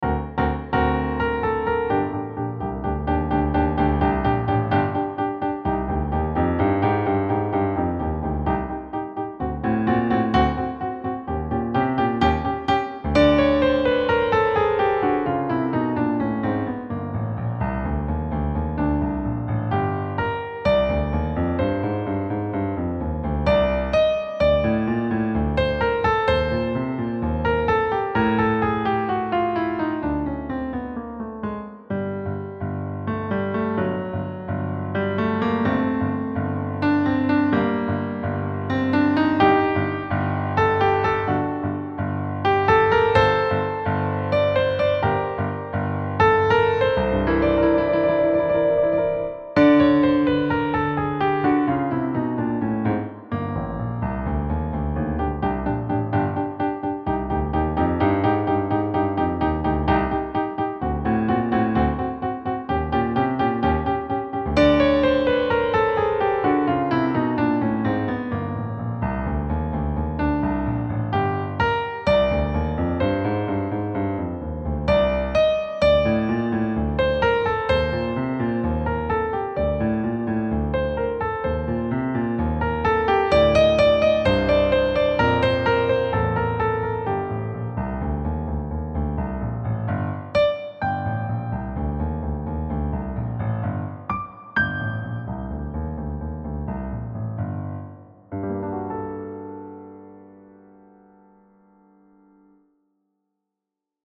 Key: G harmonic minor
Time Signature: 4/4 (BPM ≈ 128)